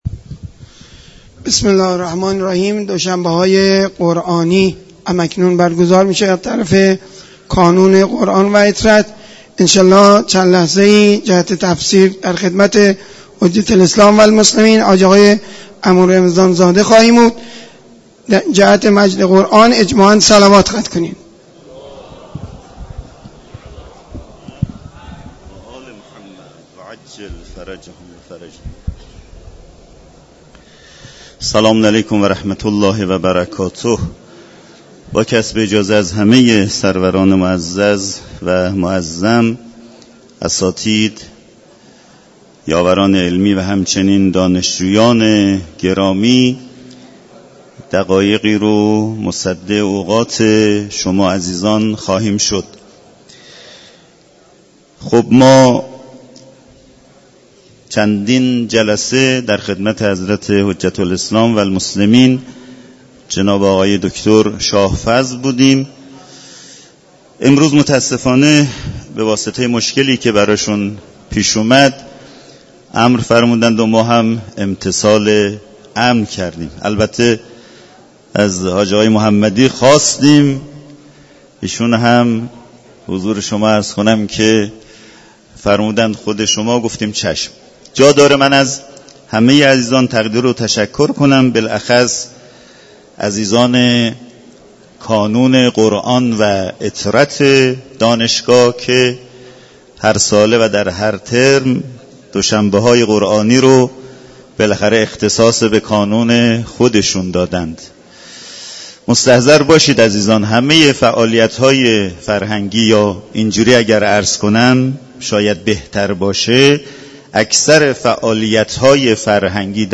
مراسم معنوی دوشنبه های قرآنی در مسجد دانشگاه کاشان برگزار شد.